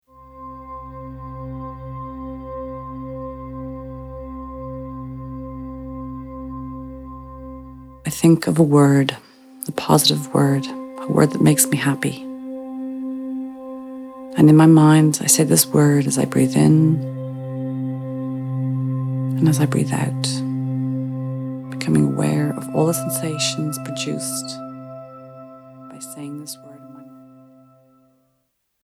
Synchronized+Breathing+excerpt.mp3